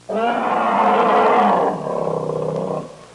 Grizzly Bear Sound Effect
Download a high-quality grizzly bear sound effect.
grizzly-bear.mp3